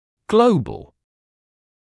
[‘gləubl][‘глоубл]глбальный; всеобщий; всемирный